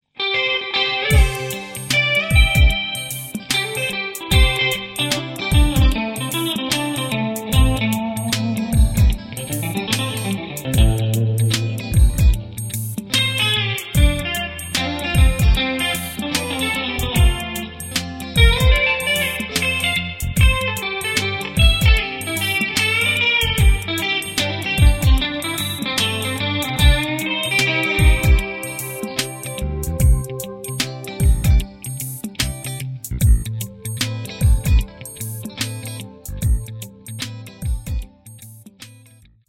Ricordate sempre la divisione terzinata della ritmica a sedicesimi.
Iniziamo subito con un classico lick country, dato dall'uso delle doppie note in slide. Tutta la linea melodica è basata sulla pentatonica maggiore di C. Attenzione alla diteggiatura allargata della parte finale in battuta 2.
Sul quarto movimento della battuta successiva abbiamo un altro lick tipicamente country, formato da un release della nota A che scende a G. Per ottenere l'effetto del release, il terzo dito aveva precedentemente tirato la seconda corda all'ottavo tasto.
Si finisce con il classico bending e release in pieno stile country, che sulla terza corda porta la nota D a E (bending), a D (release) e di nuovo a E (bending), mentre sulle prime due corde un barrè del dito mignolo ci consente di suonare le note C (prima corda) e G (seconda corda).